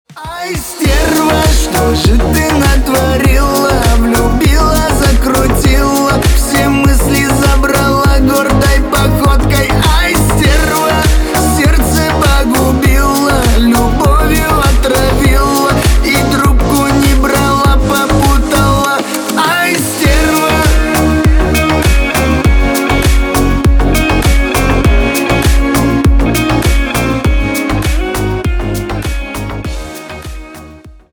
на русском восточные на бывшую